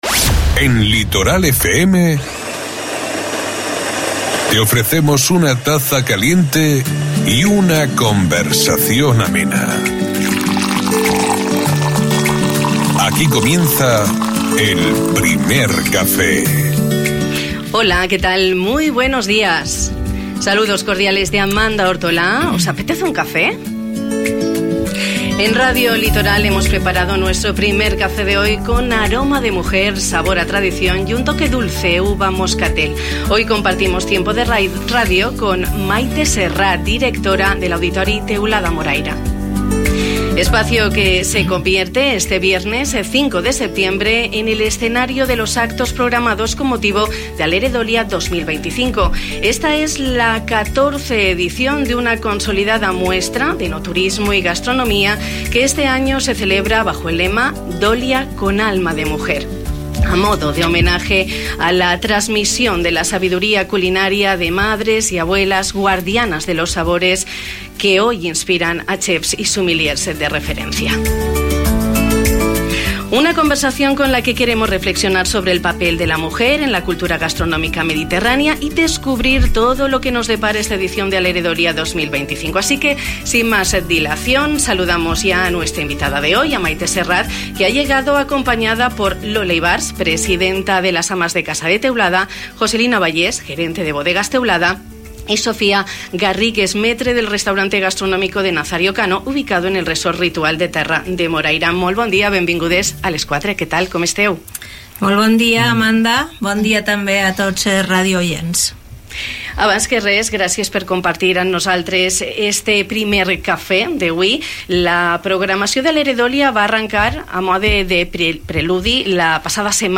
Una conversa amb què hem volgut reflexionar sobre el paper de la dona a la cultura gastronòmica mediterrània i descobrir tot el que ens ofereix aquesta edició d'Alere Dolia 2025.